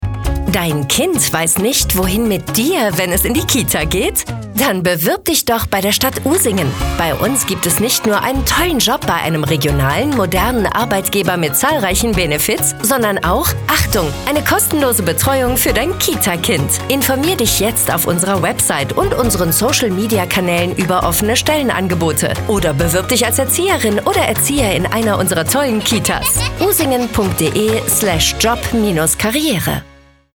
radiospot-stadtverwaltung-usingen-recruiting.mp3